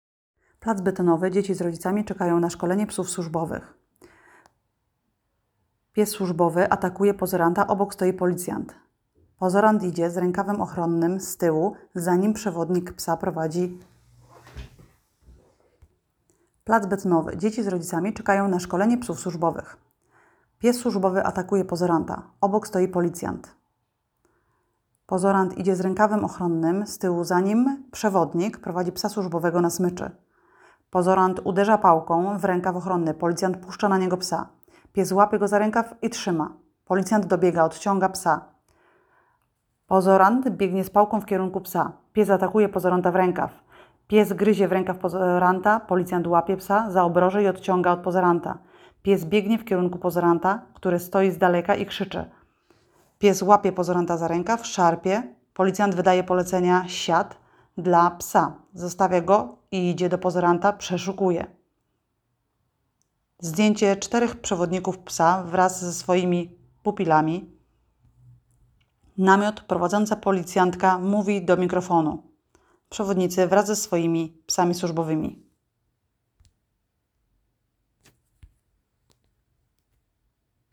Nagranie audio audiodeskrypcja_filmu.m4a